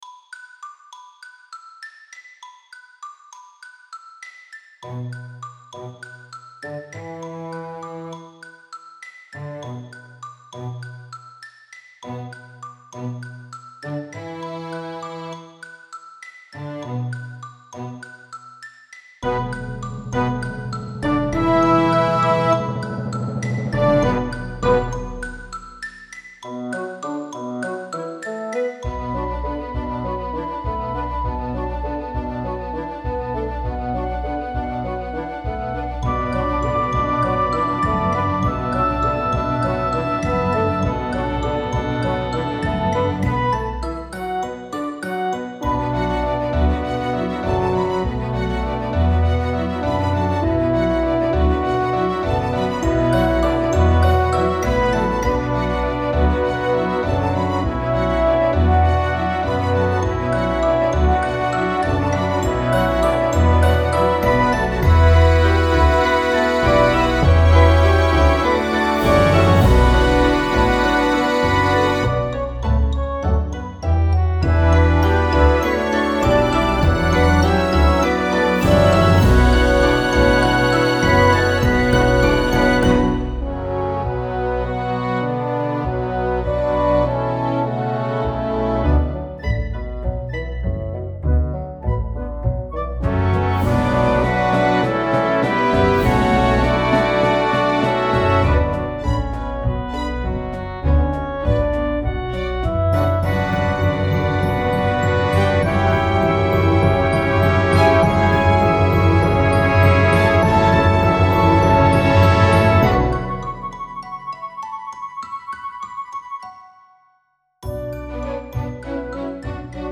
Symphony Orchestra – Advanced